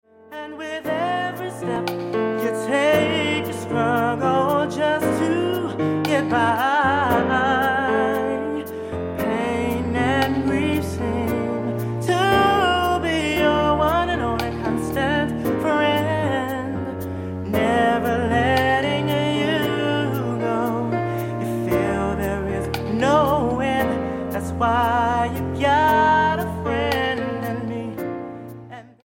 STYLE: R&B
almost just voice and solo piano